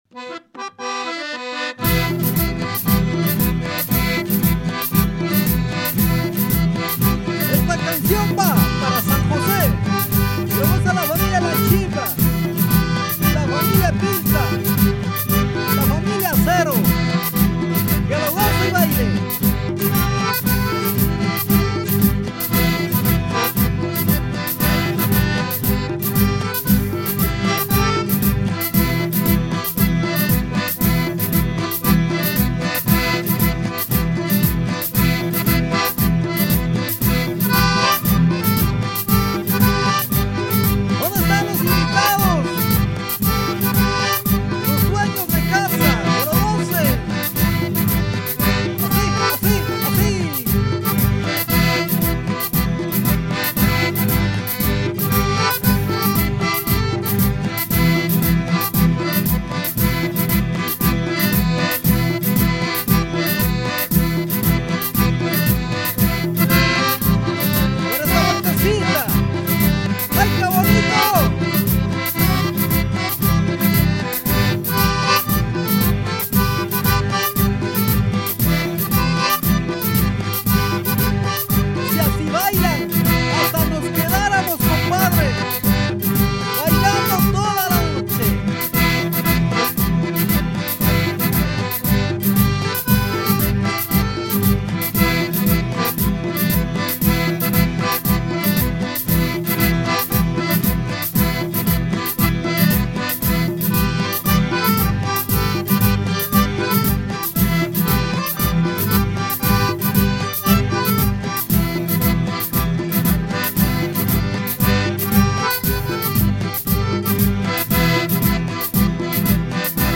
Home > Music > Pop > Bright > Folk > Running